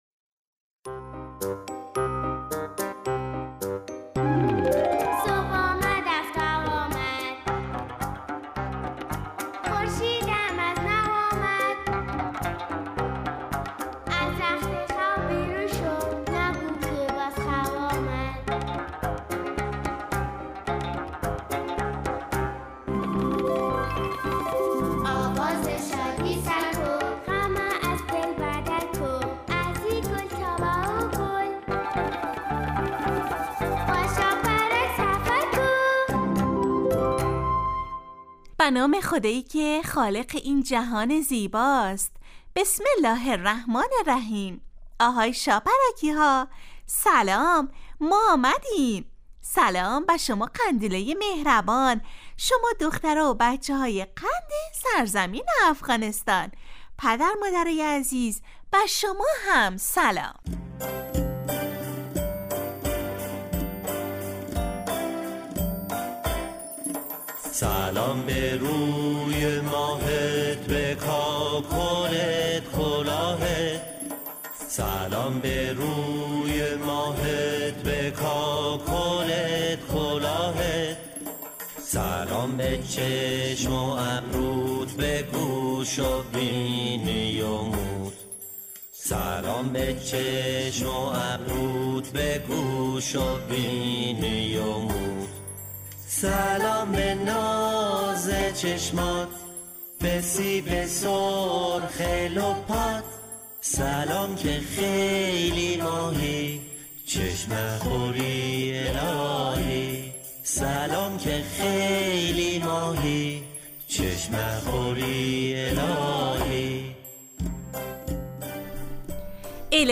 برنامه ای ترکیبی نمایشی است که برای کودکان تهیه و آماده میشود.این برنامه هرروز به مدت 15 دقیقه با یک موضوع مناسب کودکان در ساعت 8:15 صبح به وقت افغانستان از رادیو دری پخش می گردد.